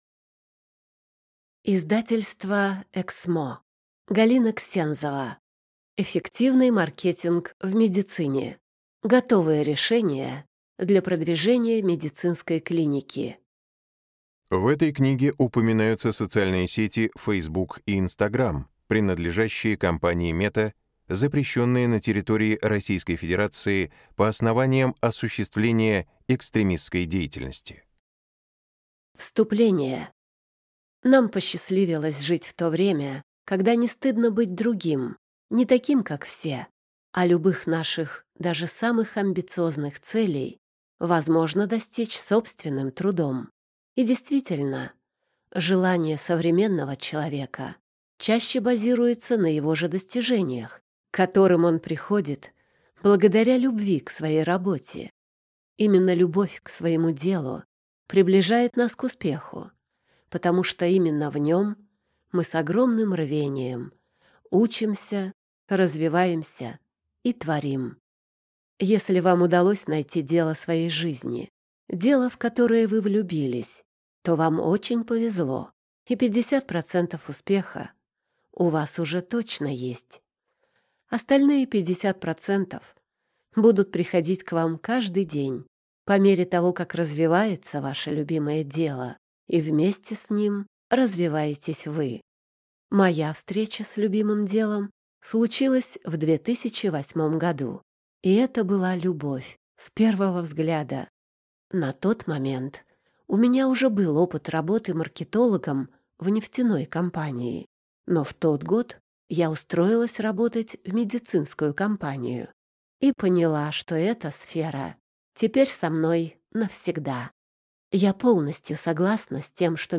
Аудиокнига Эффективный маркетинг в медицине. Готовые решения для продвижения медицинской клиники | Библиотека аудиокниг